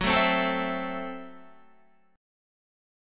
Gbm7b5 chord